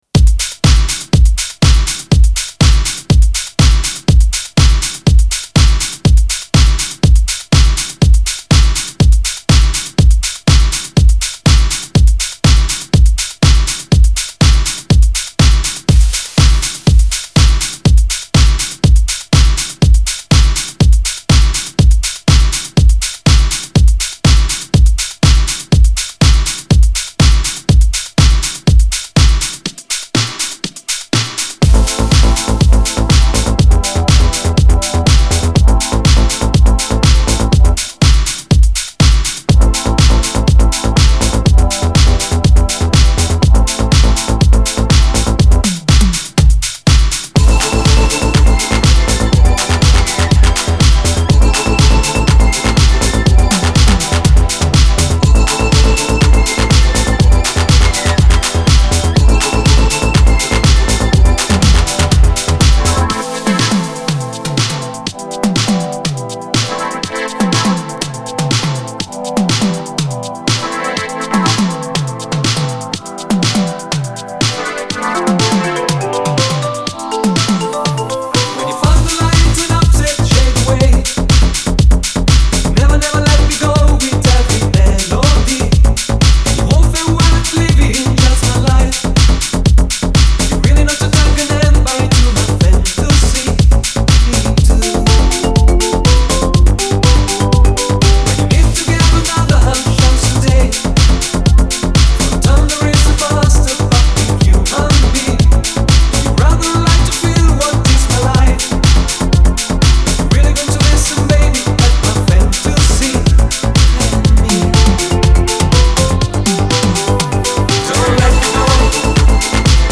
Genre : Alternative & Indie